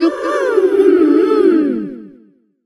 tara_start_vo_02.ogg